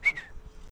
Whistle Quick.wav